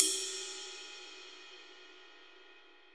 Index of /90_sSampleCDs/Best Service - Real Mega Drums VOL-1/Partition H/DRY KIT 2 GM
RIDE 3.wav